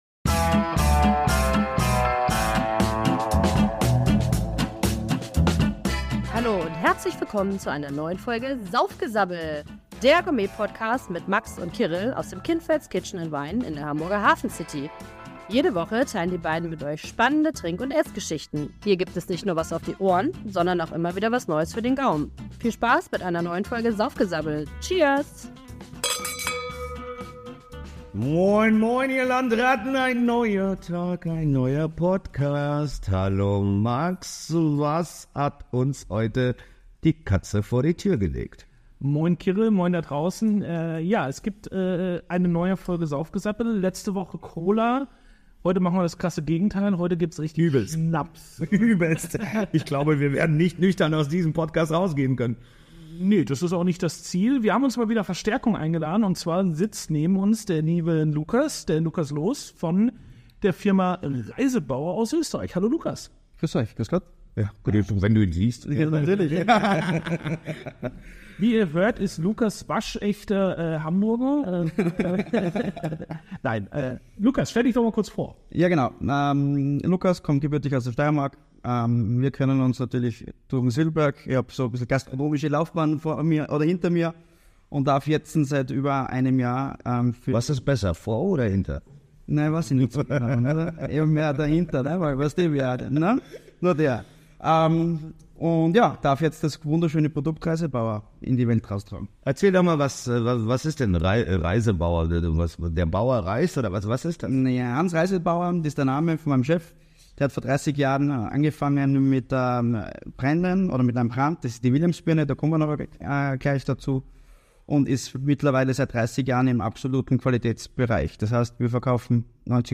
Handwerk, Qualität und Schnapskultur – Im Gespräch mit Reisetbauer ~ Saufgesabbel